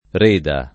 Reda [ r % da ]